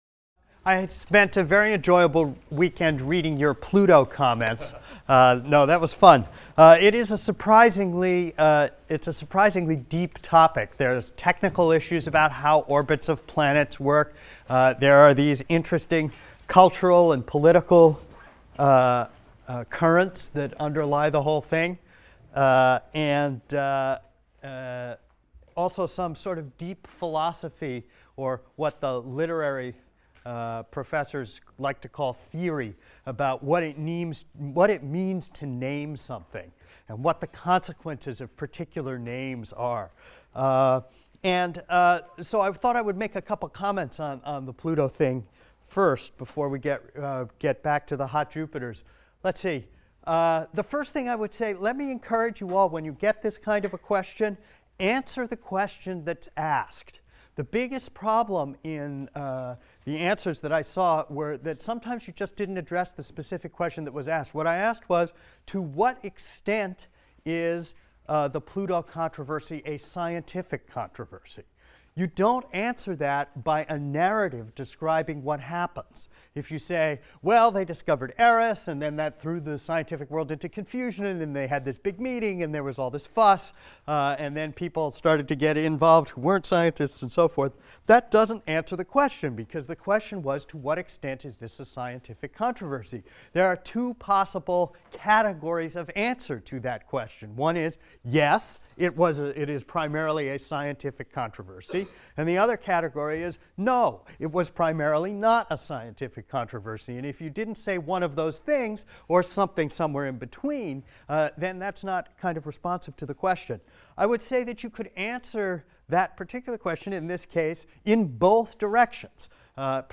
ASTR 160 - Lecture 5 - Planetary Transits | Open Yale Courses